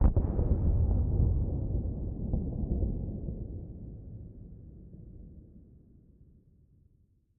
basaltground1.ogg